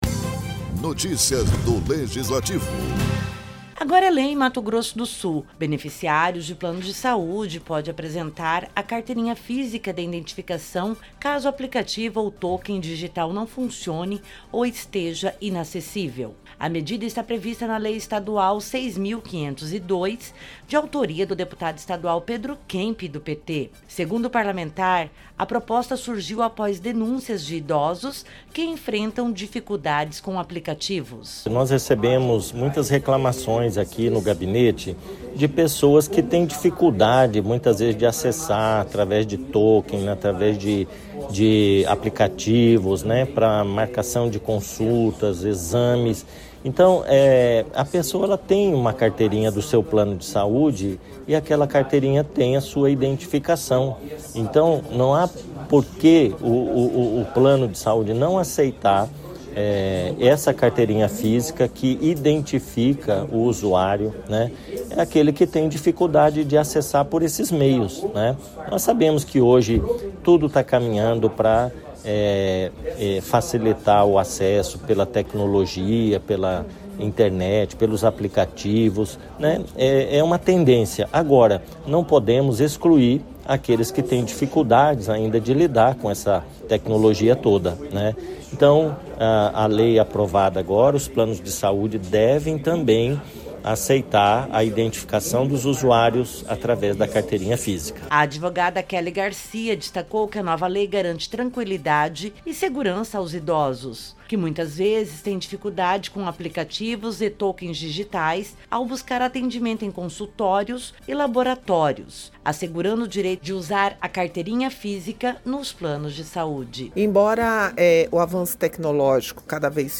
Produção e Locução